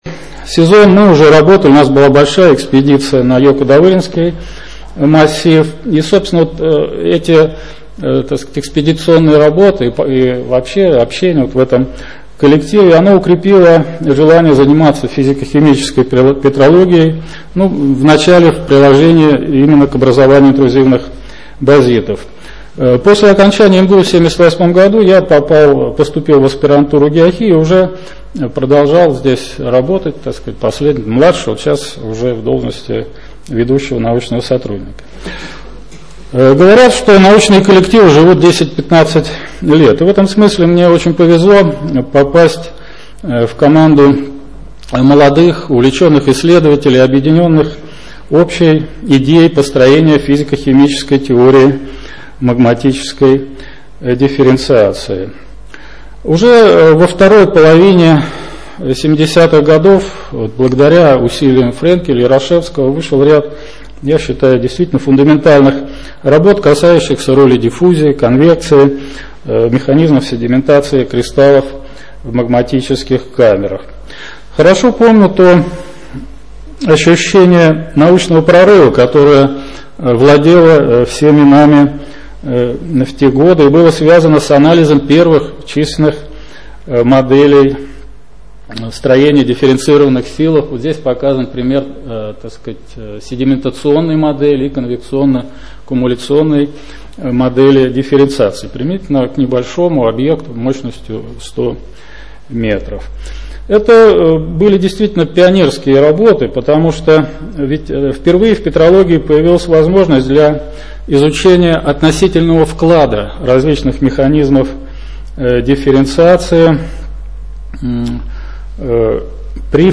Лекции
Запись лекции Доклад-презентация